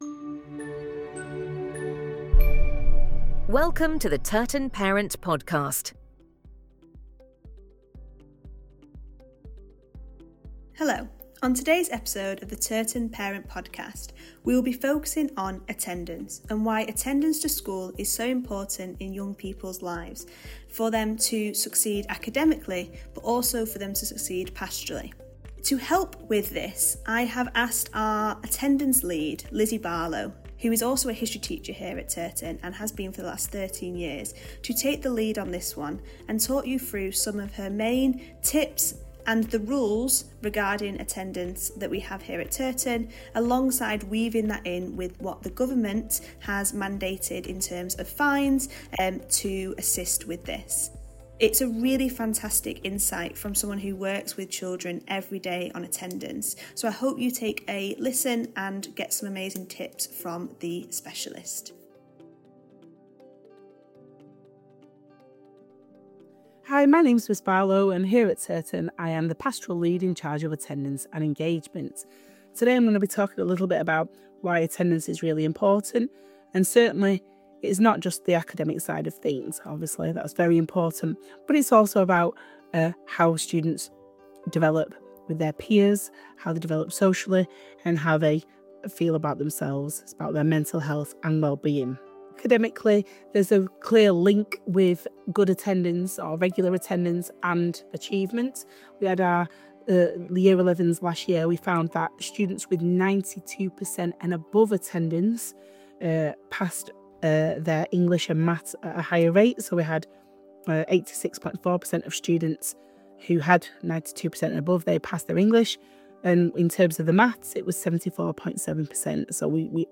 TP-Podcast-Ep-12_mixdown_w_music.mp3